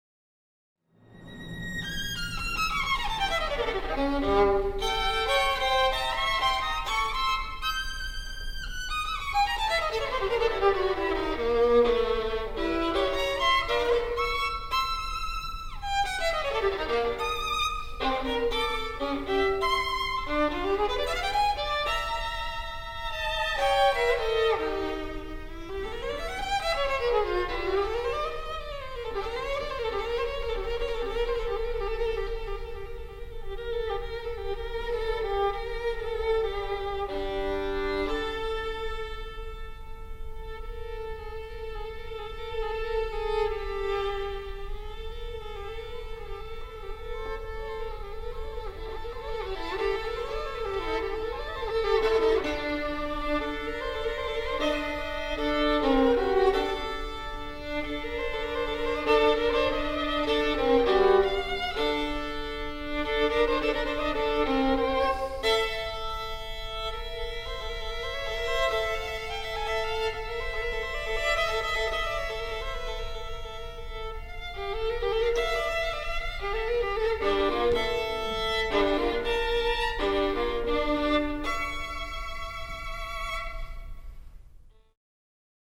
violin & orchestra